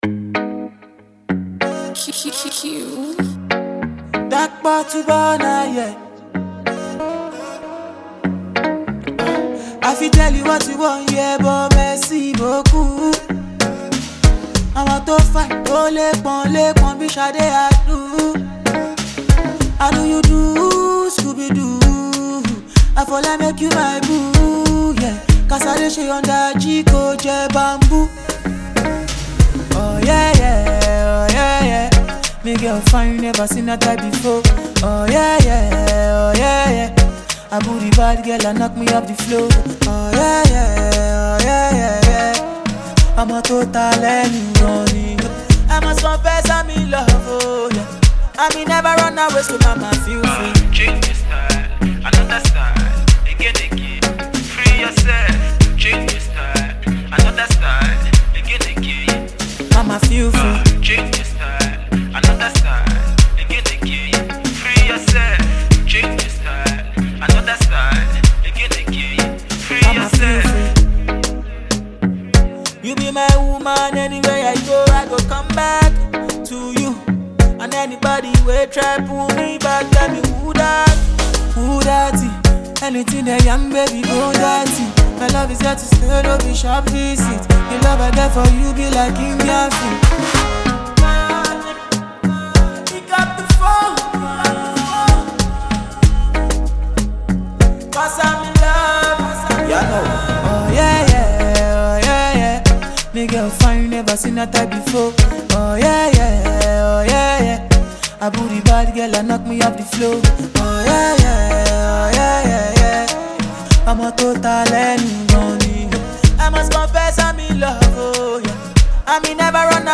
With a fresh play on words and afrobeat rhythms